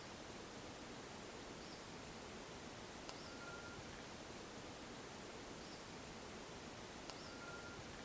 Solitary Tinamou (Tinamus solitarius)
Location or protected area: Parque Provincial Cruce Caballero
Condition: Wild
Certainty: Recorded vocal